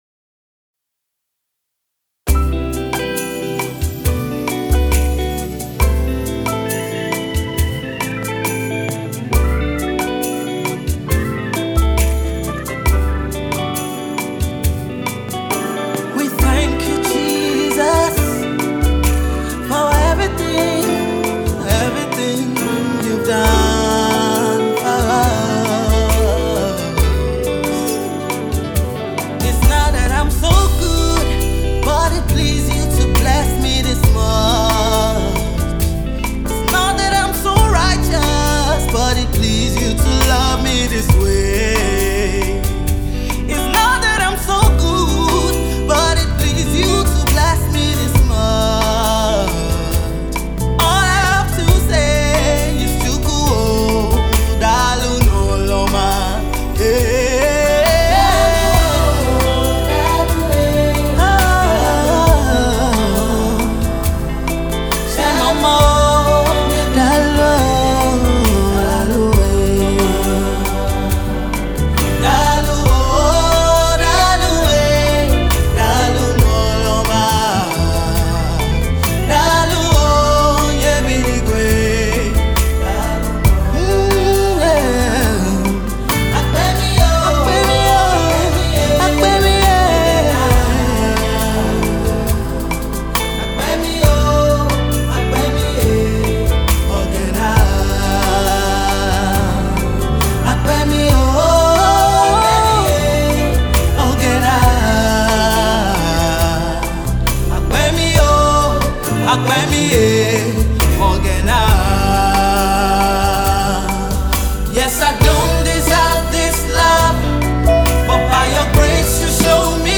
Contemporary singer/songwriter
Delivering a soul-stirring and uplifting sound
heartfelt worship and thanksgiving to God